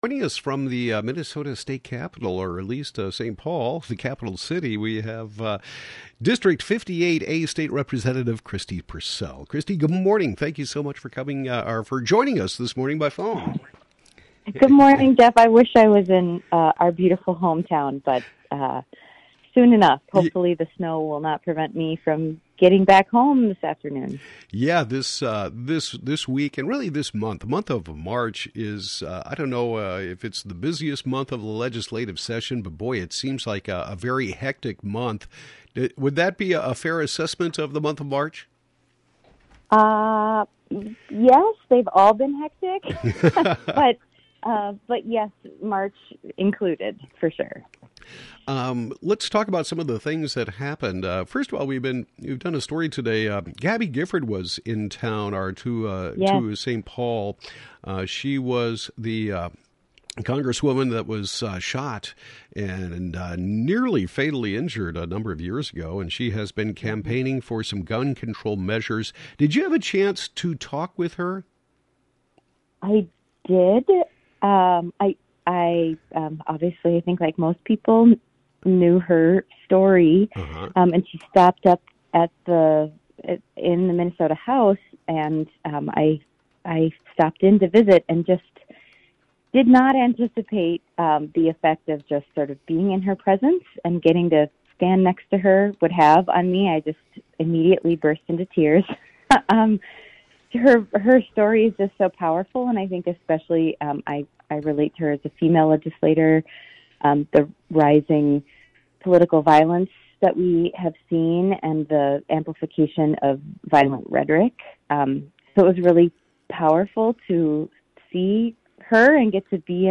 District 58A State Representative Kristi Pursell discusses meeting former Representative Gabby Giffords who was in St. Paul to urge lawmakers to pass laws aimed at curbing gun violence, and she provides her weekly legislative update.